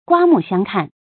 注音：ㄍㄨㄚ ㄇㄨˋ ㄒㄧㄤ ㄎㄢˋ
刮目相看的讀法